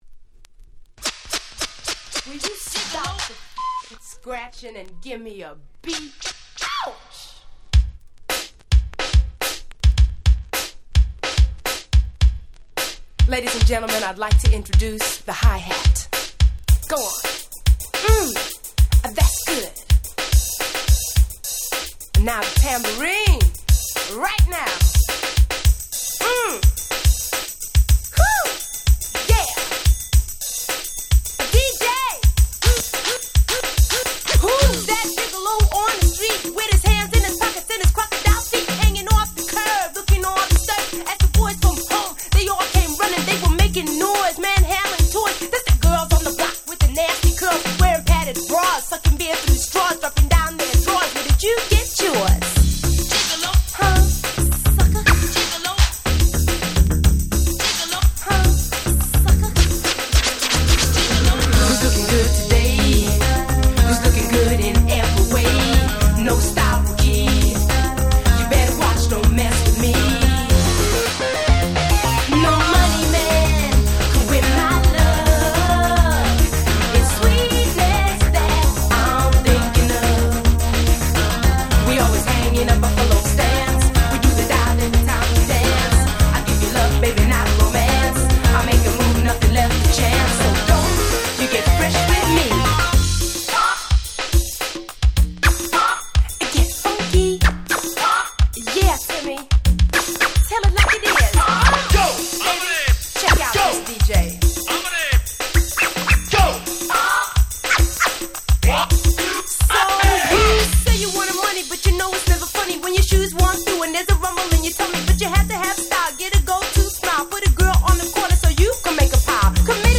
88' Super Hit R&B !!
80年代後半を代表する軽快なダンスナンバーです！